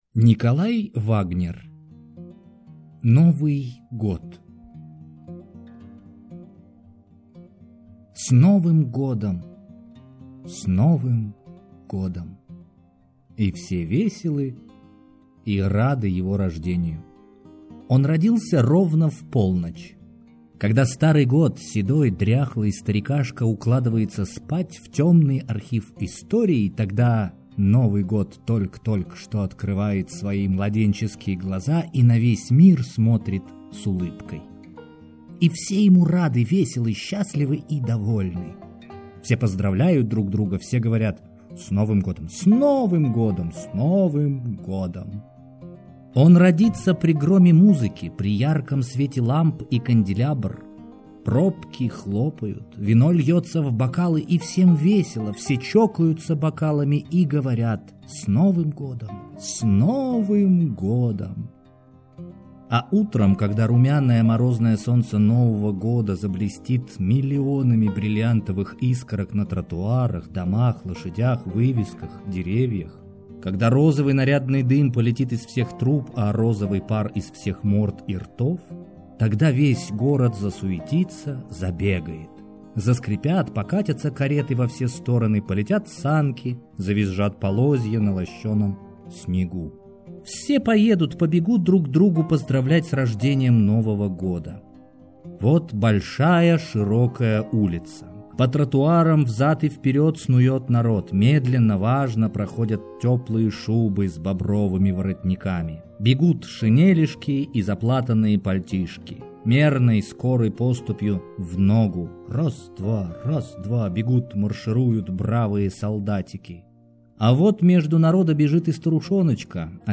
Аудиокнига Новый год | Библиотека аудиокниг